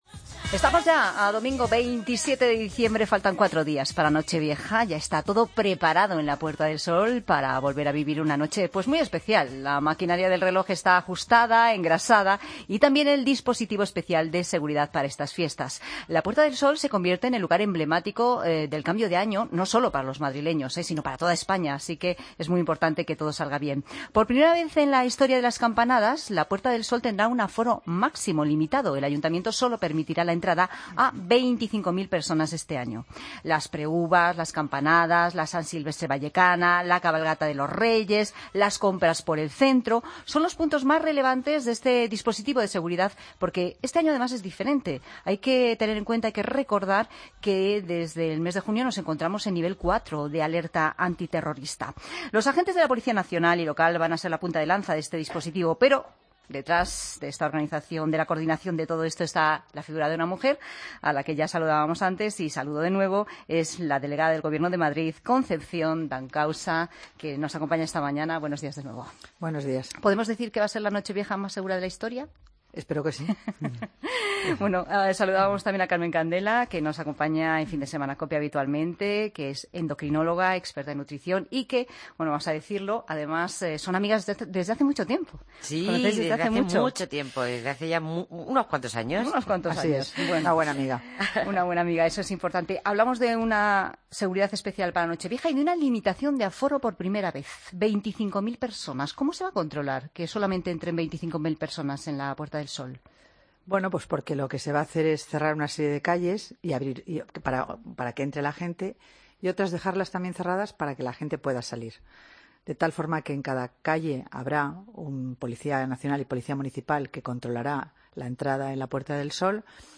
AUDIO: Escucha la entrevista a Concepción Dancausa, Delegada del Gobierno en Madrid, en Fin de Semana.